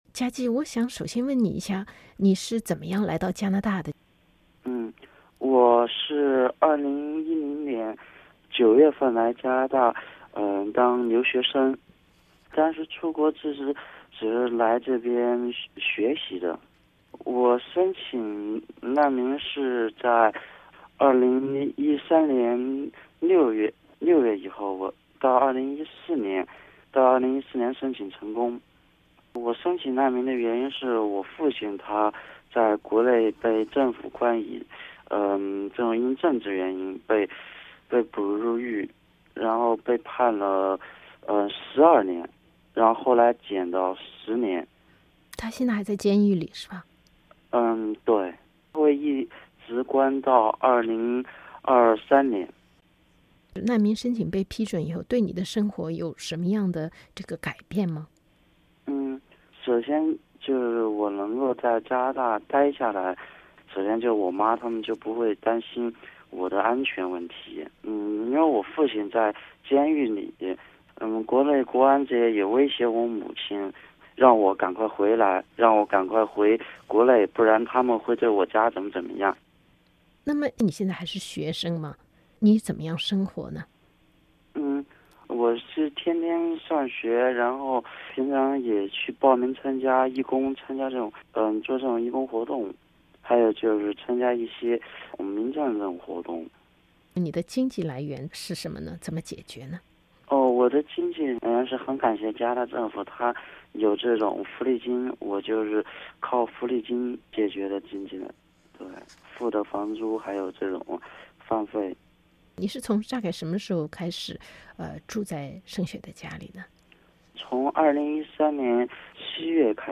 只有在谈到学校，谈到做义工时的见闻和感想时，他的话才密一些，声调也活泼起来。